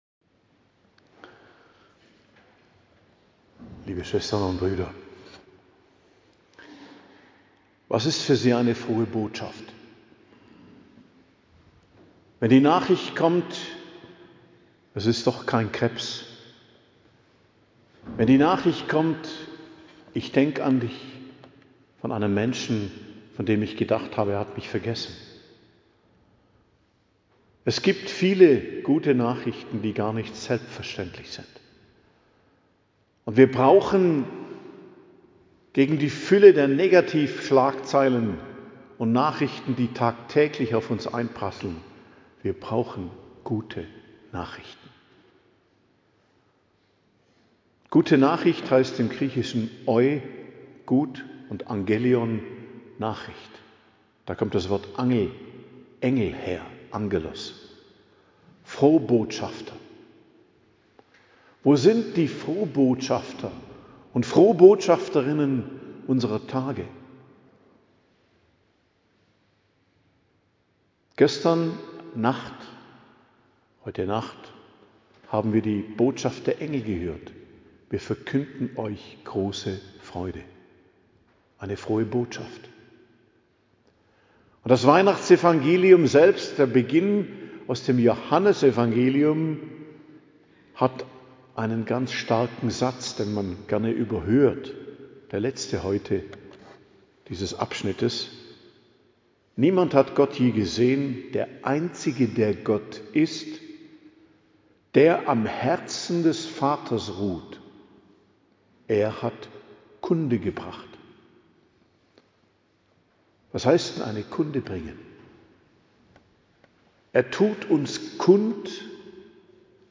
Predigt zu Weihnachten - Hochfest der Geburt des Herrn, 25.12.2025